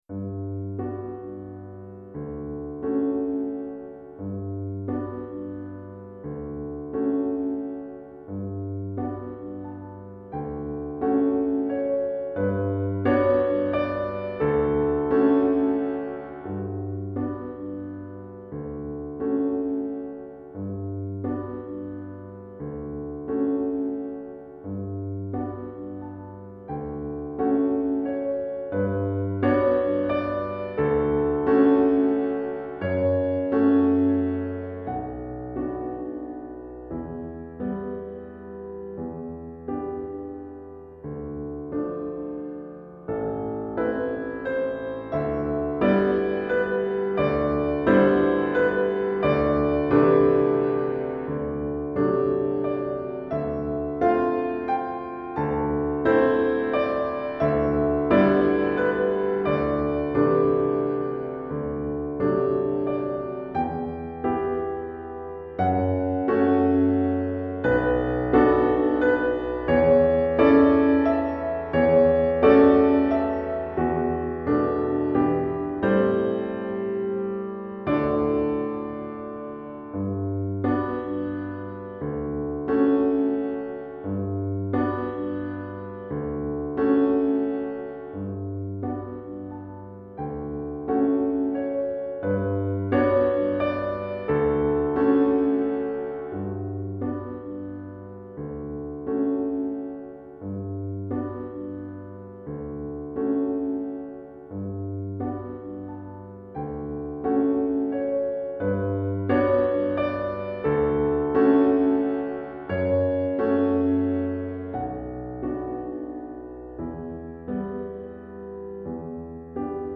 Répertoire pour Piano